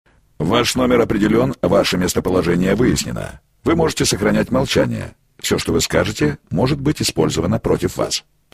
Звуки автоответчика
Здесь вы найдете классические сигналы, голосовые приветствия и характерные гудки.
Звук с фразой для автоответчика КГБ